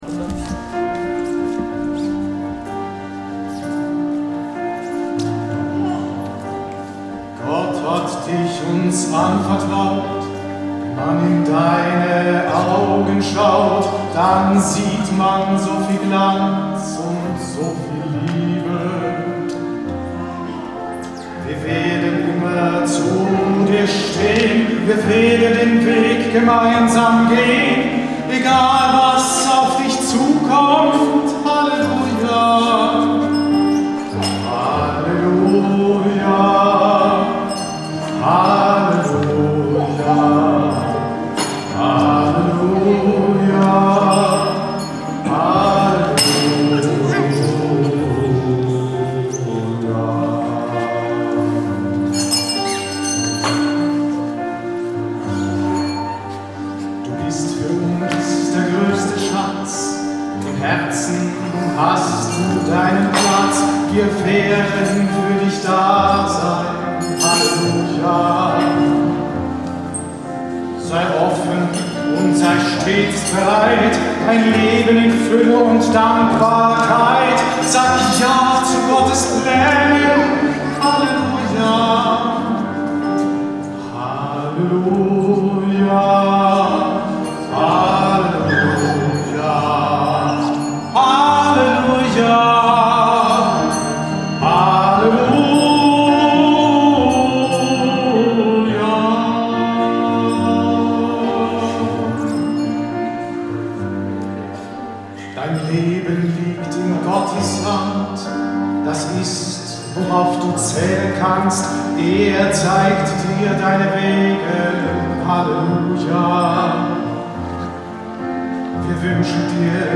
Live-Mitschnitte:
hallelujah-taufversion.mp3